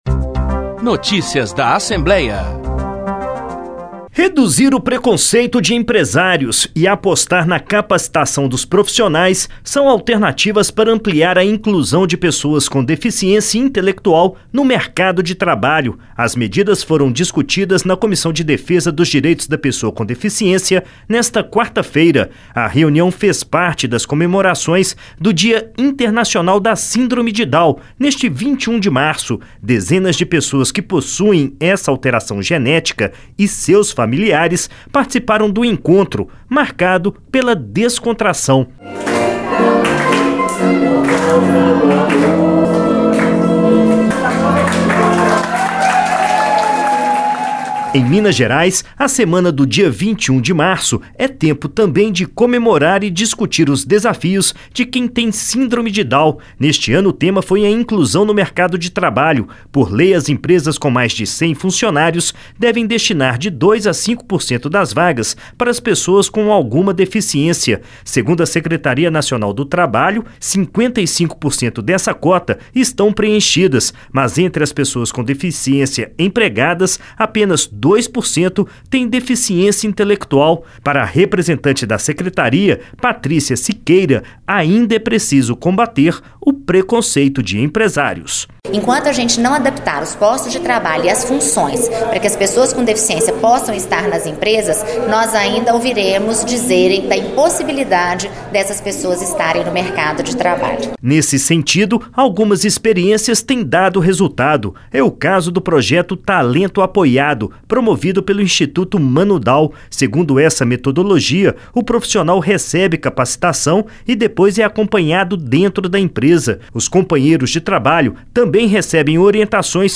Essa cobrança aconteceu em audiência pública realizada nesta quarta-feira (20/3/19), pela Comissão de Defesa dos Direitos da Pessoa com Deficiência da Assembleia Legislativa de Minas Gerais (ALMG), em alusão ao Dia Internacional da Síndrome de Down, celebrado mundialmente nesta quinta (21).